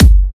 Kick 26 (Lick It).wav